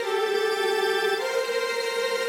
Index of /musicradar/gangster-sting-samples/105bpm Loops
GS_Viols_105-AC.wav